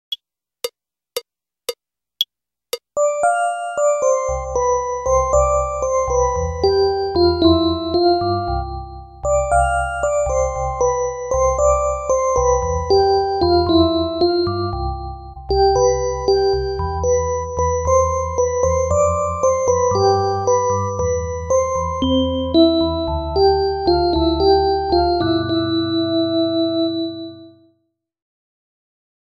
延長4駅車内メロディー